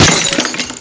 bottle.wav